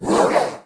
monster / skeleton_magician / attack_1.wav
attack_1.wav